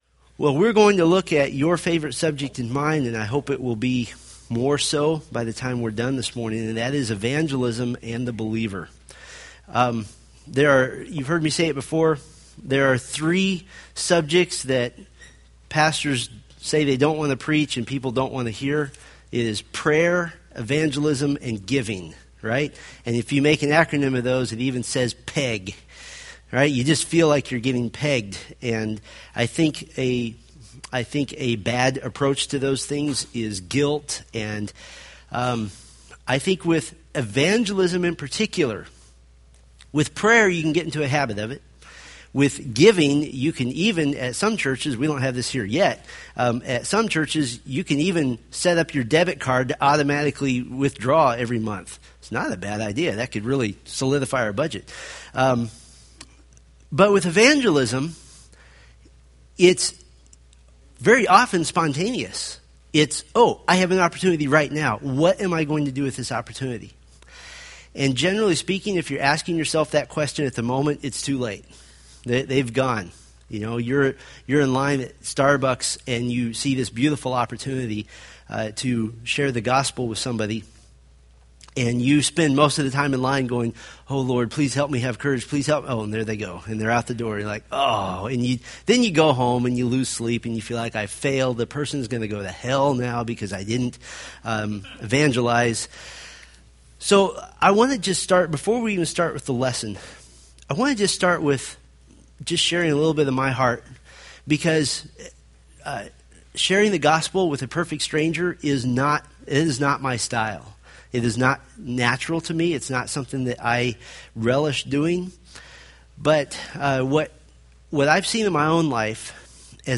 Date: Sep 22, 2013 Series: Fundamentals of the Faith Grouping: Sunday School (Adult) More: Download MP3